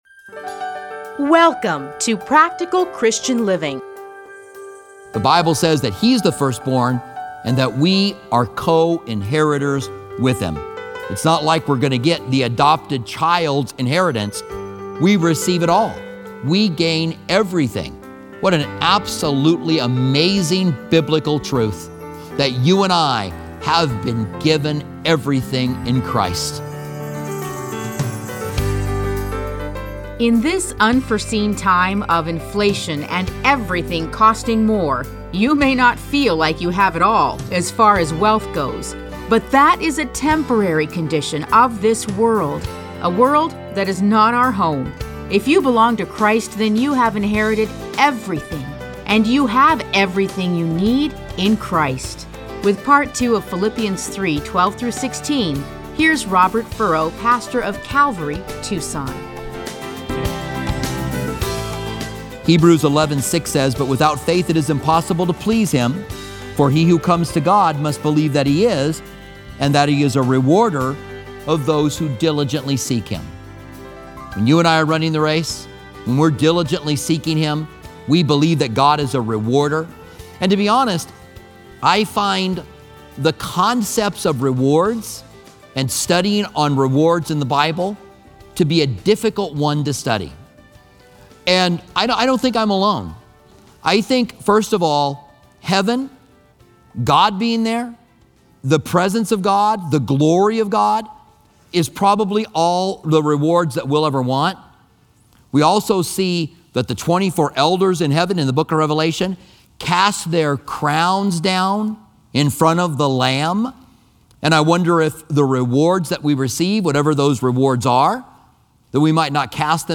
Listen to a teaching from A Study in Philippians 3:12-16 .